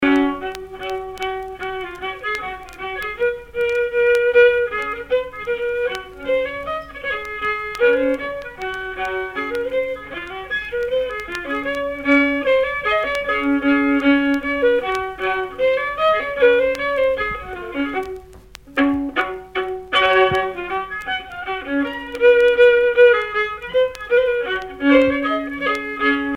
danse : scottich sept pas
Pièce musicale éditée